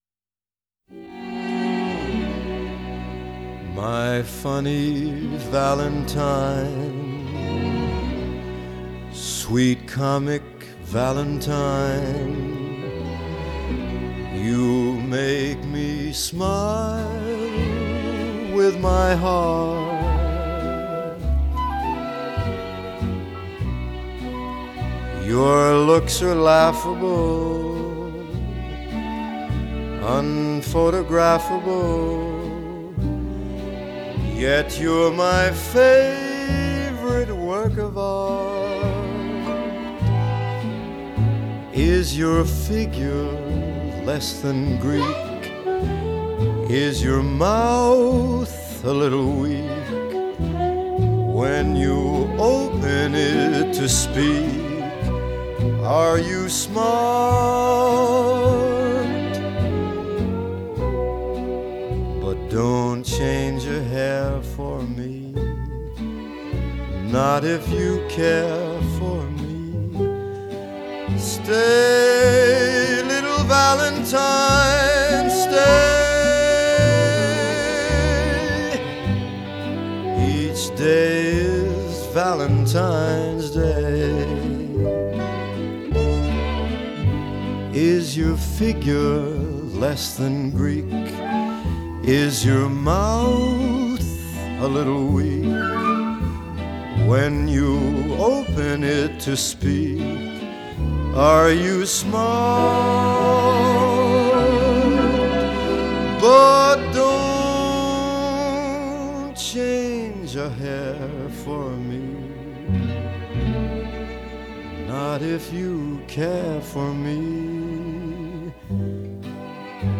Jazz, Pop Standards, Vocal Jazz